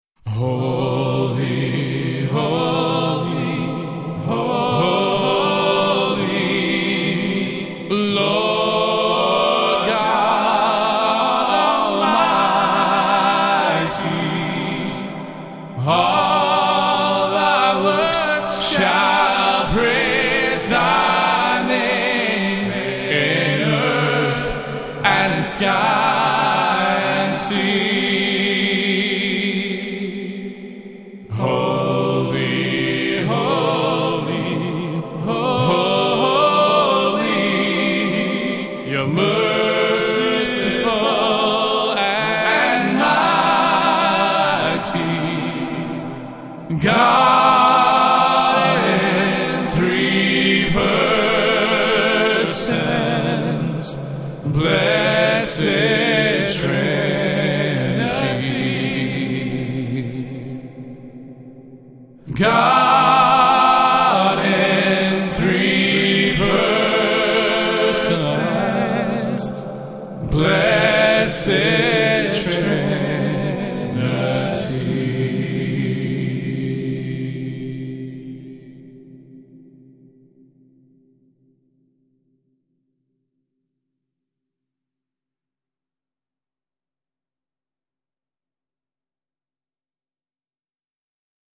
a capella reprise
These are the voice tracks only from the last verse.